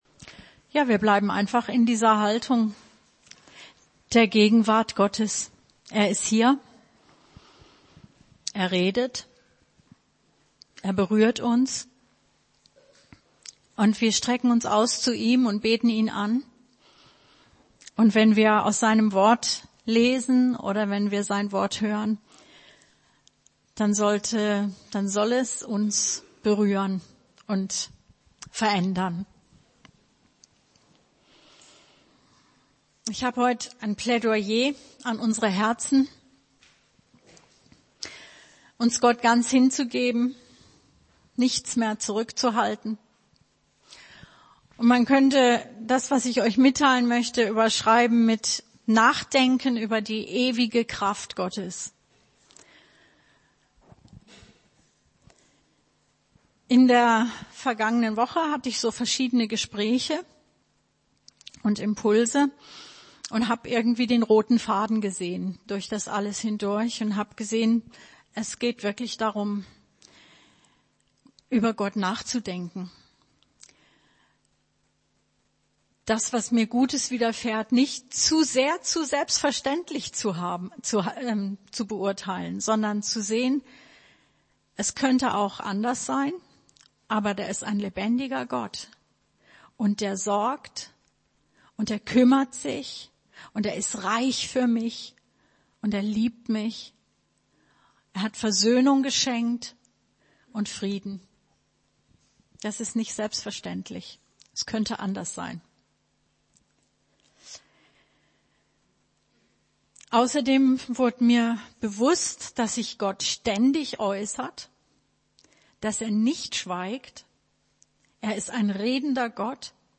Predigt 04.02.2018